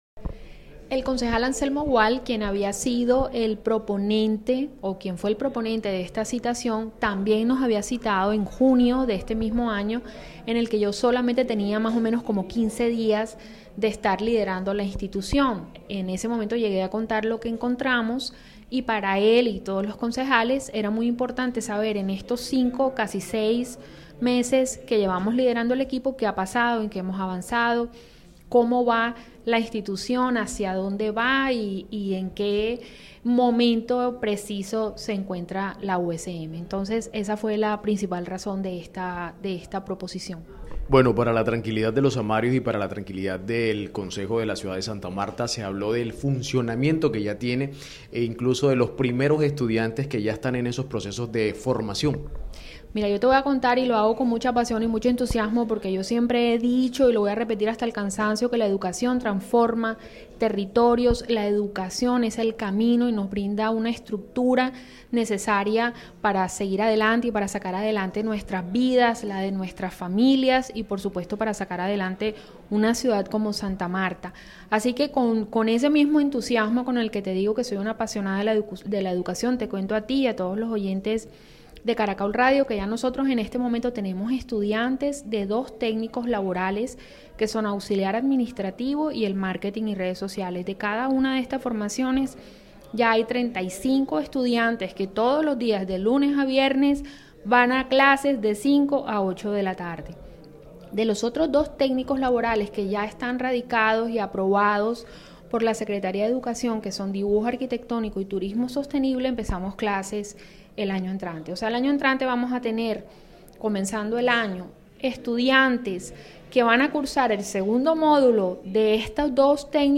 En diálogo con Caracol Radio